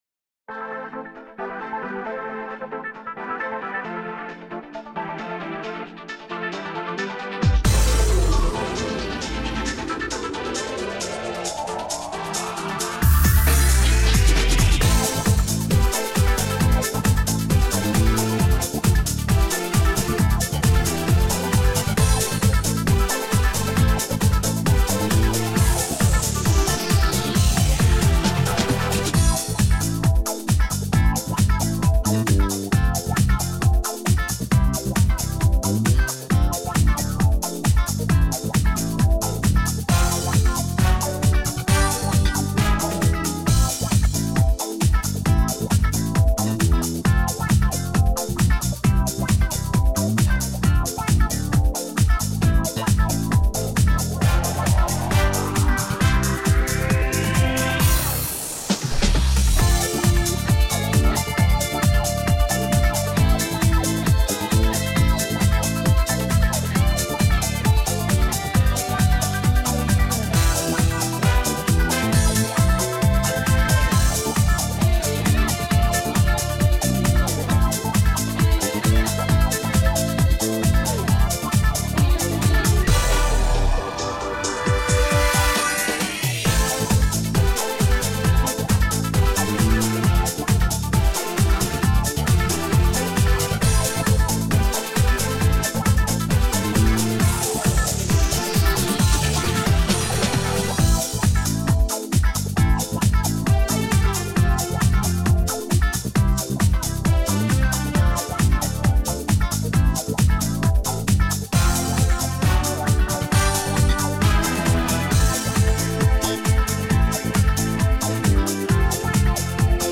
минусовка версия 98281